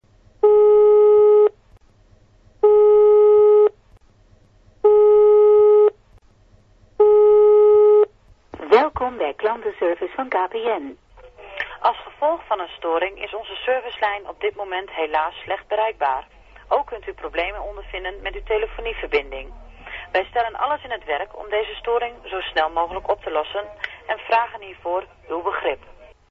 Nieuwe ringtone
Gisteravond een beetje zitten spelen met wat geluiden en heb een nieuwe ringtone op mijn mobiel gezet: